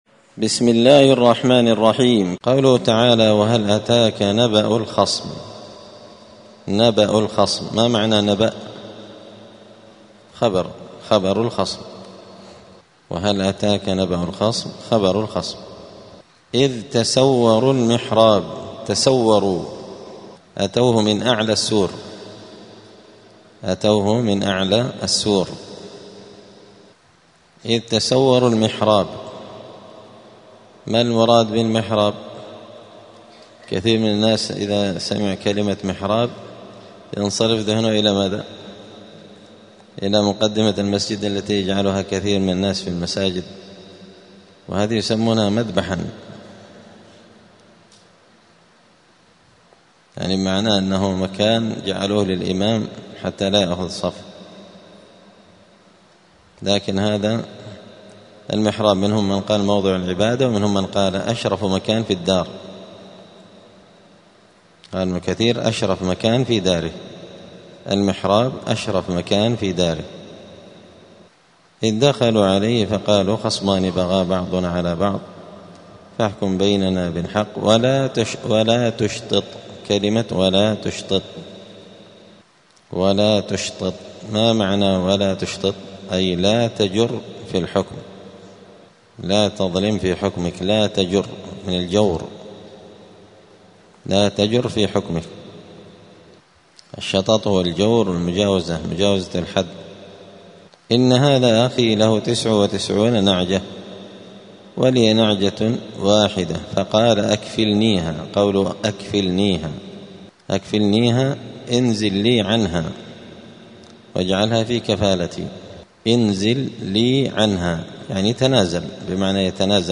الأثنين 21 ربيع الثاني 1447 هــــ | الدروس، دروس القران وعلومة، زبدة الأقوال في غريب كلام المتعال | شارك بتعليقك | 5 المشاهدات